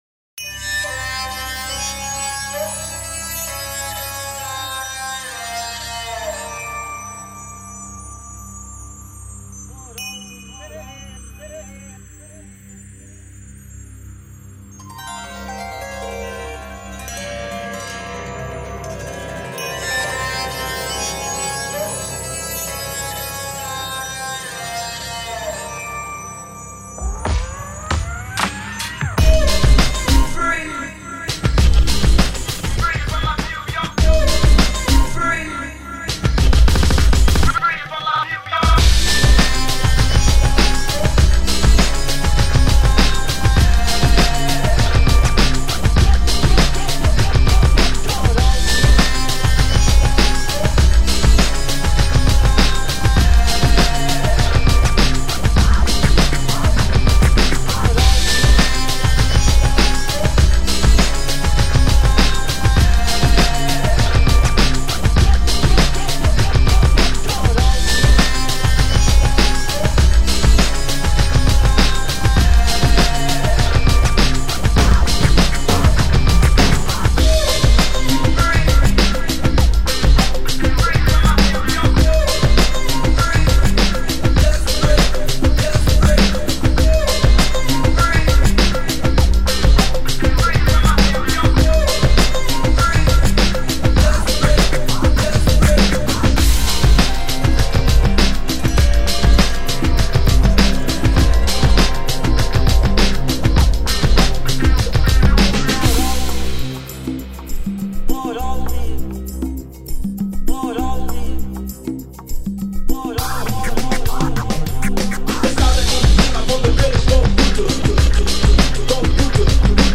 worldly, upbeat exotic grooves...
meet middle eastern, hip hop, dub textures.